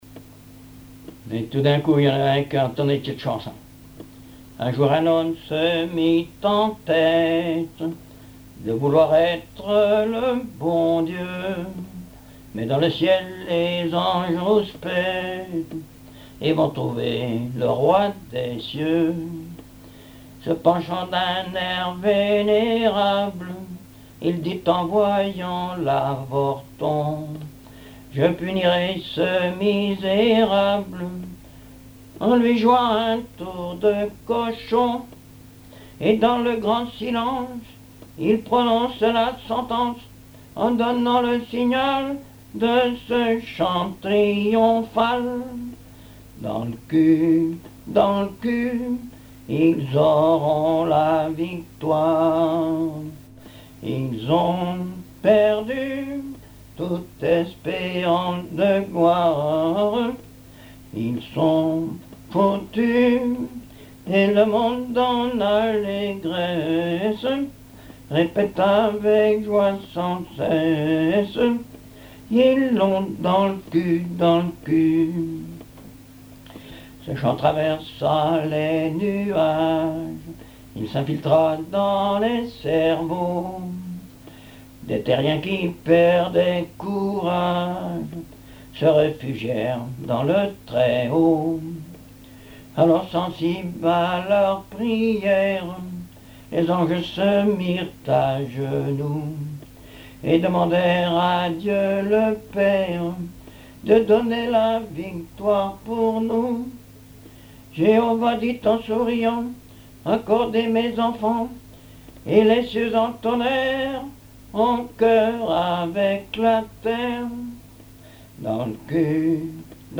Genre strophique
contes, récits et chansons populaires
Pièce musicale inédite